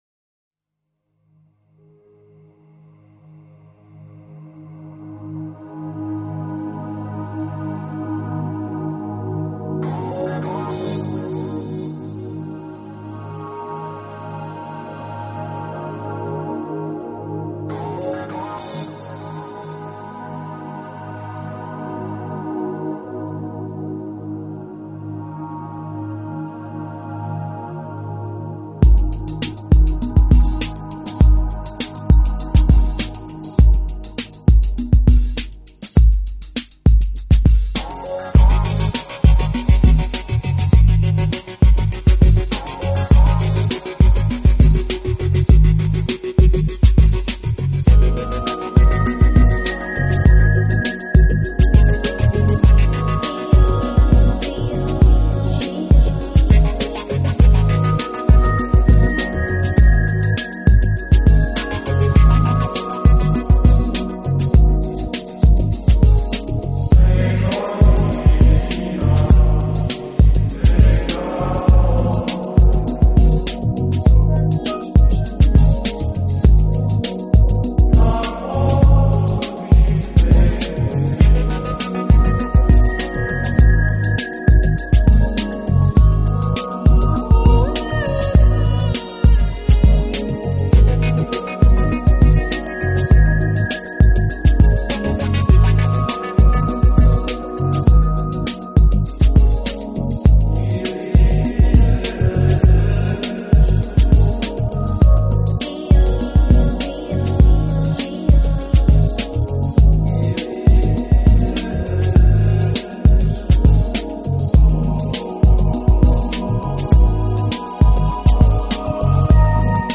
录制唱片，由于善于把握时尚元素，将Smooth Jazz与电子、舞曲风格完美结合，
旋律轻柔流畅，器乐创新搭配，节奏舒缓时尚，魅力女声
如同和煦清爽的凉风与清凉透心的泉水流淌全身，令人舒适之极。